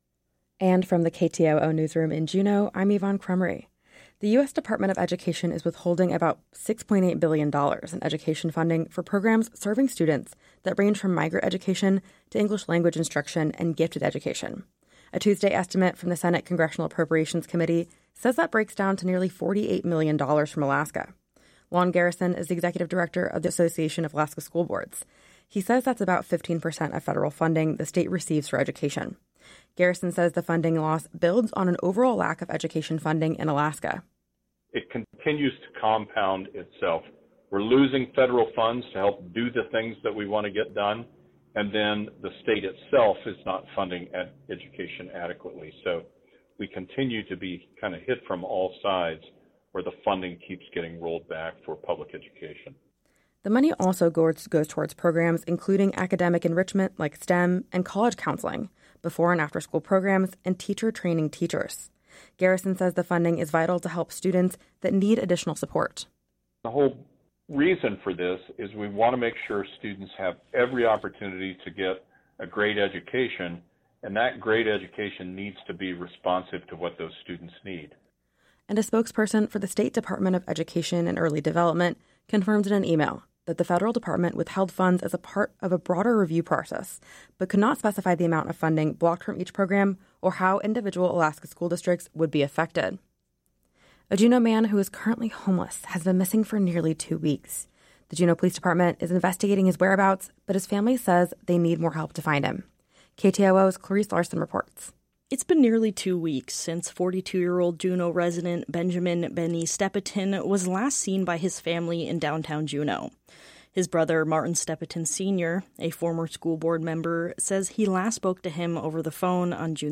Newscast – Thursday, July 3, 2025 - Areyoupop